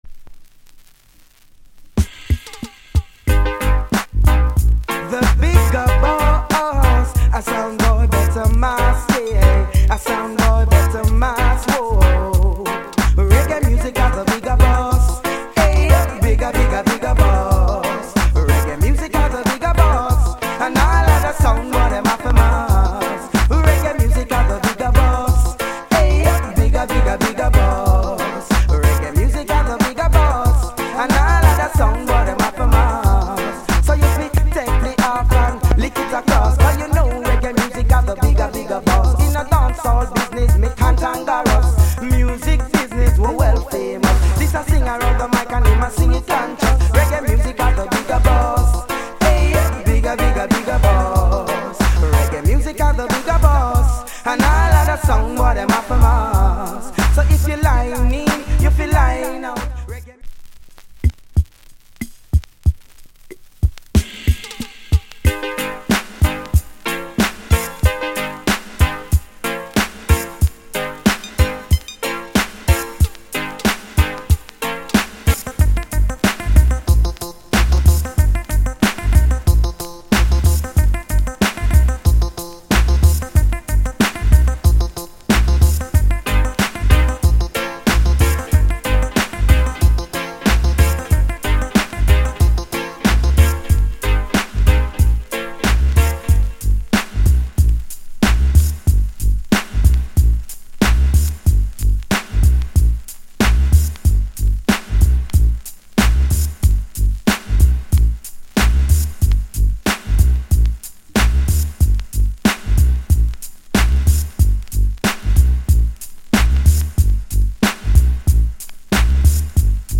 * '89 Hit Rub A Dub Tune!